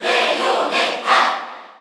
Category: Crowd cheers (SSBU) You cannot overwrite this file.
Bayonetta_Cheer_Korean_SSBU.ogg.mp3